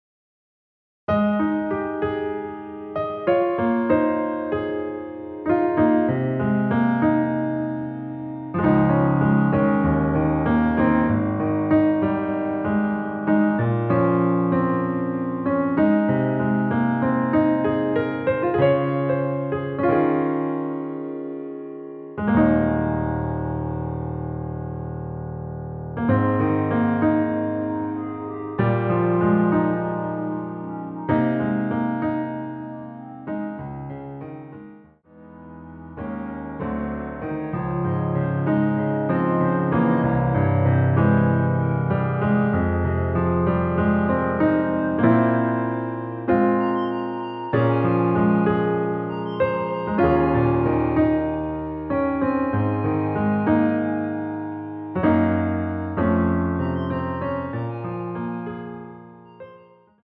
원키에서(-2)내린 멜로디 포함된 MR입니다.
Ab
앞부분30초, 뒷부분30초씩 편집해서 올려 드리고 있습니다.
중간에 음이 끈어지고 다시 나오는 이유는